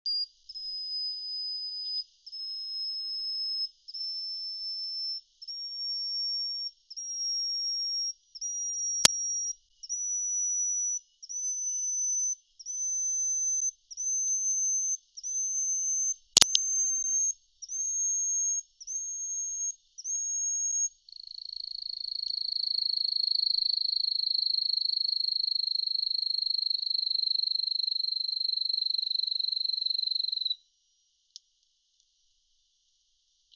30-7塔塔加2012mar26深山鶯song3g.mp3
物種名稱 黃腹樹鶯 Cettia acanthizoides concolor
錄音地點 南投縣 信義鄉 塔塔加
錄音環境 森林
行為描述 鳥叫
錄音: 廠牌 Denon Portable IC Recorder 型號 DN-F20R 收音: 廠牌 Sennheiser 型號 ME 67